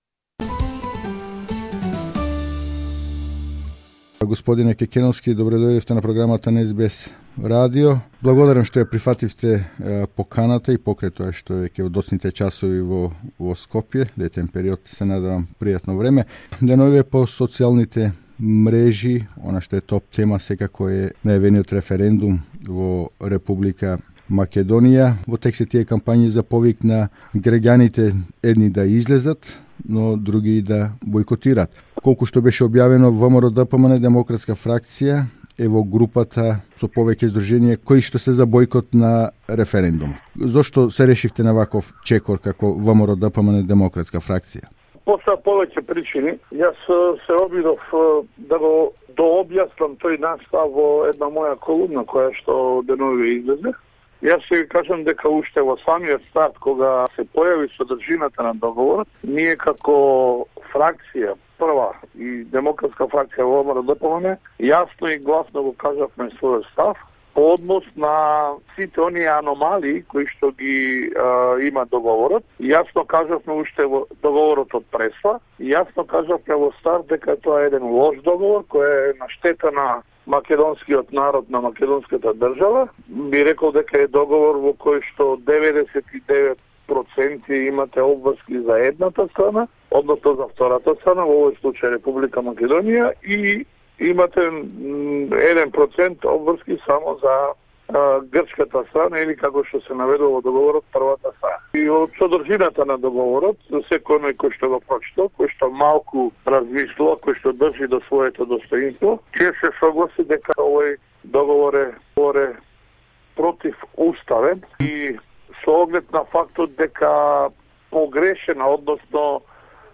Проф. д-р Јове Кекеновски од ВМРО ДПМНЕ - Демократска фракција во разговр за СБС радио со видување и објаснување за разликата помеѓу "не" односно "против" и "бојкотирам" на закажаниот референдум за 30 септември. Според Кекеновски и правото на бојкот е демократско право и никаде во Уставот или во било кој закон пишува дека правото на бојкот е забрането.